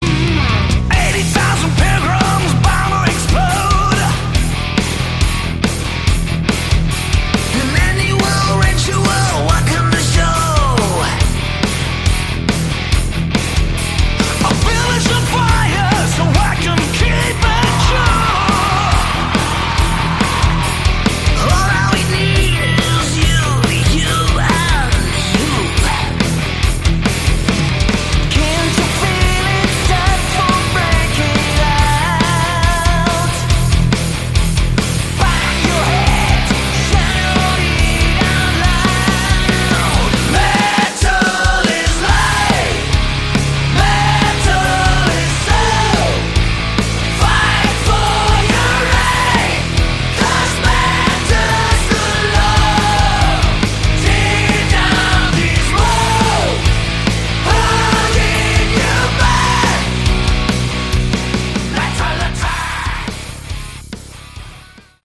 Category: Hard Rock/Melodic Metal
vocals
bass
guitars
keyboards
drums